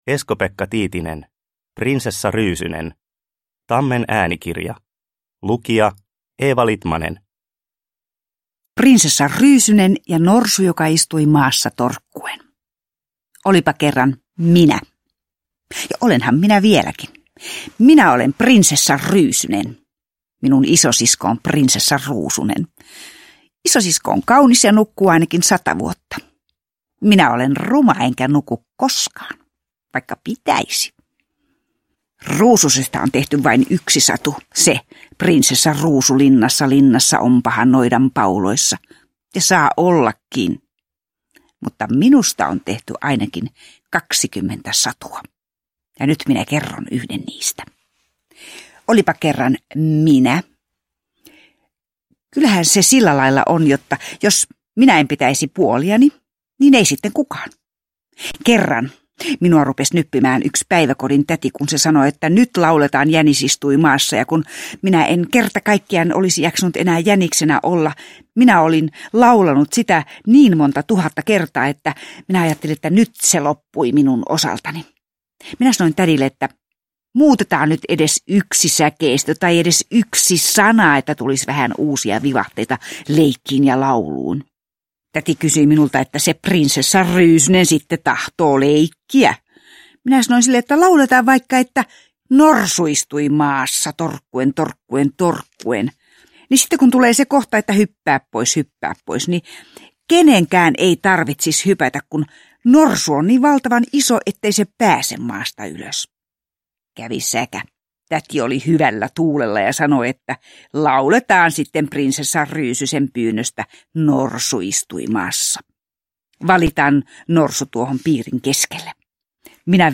Prinsessa Ryysynen – Ljudbok – Laddas ner